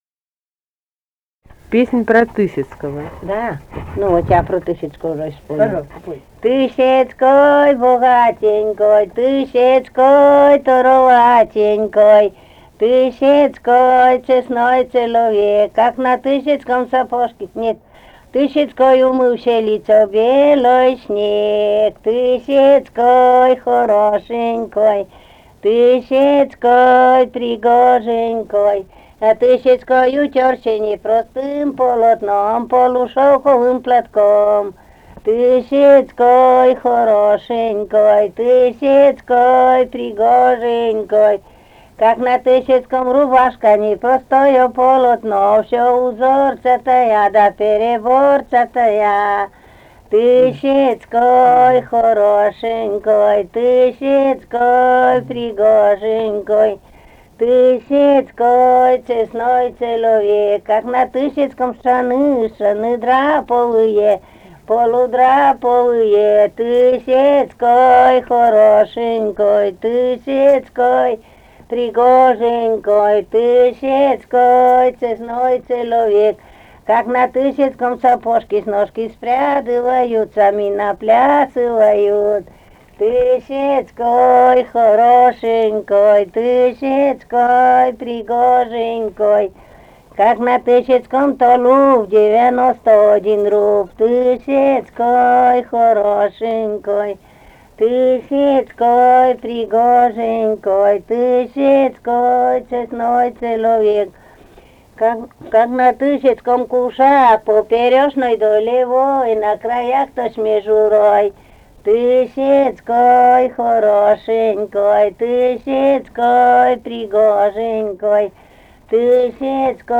Живые голоса прошлого 099. «Тысяцкой богатенькой» (свадебная).